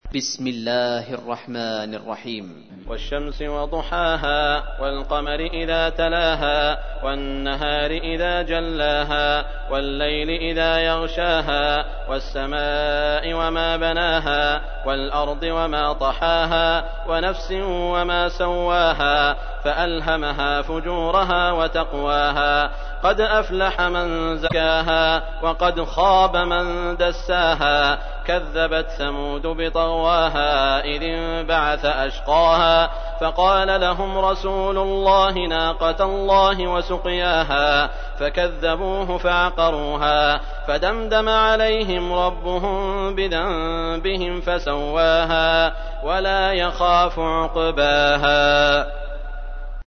تحميل : 91. سورة الشمس / القارئ سعود الشريم / القرآن الكريم / موقع يا حسين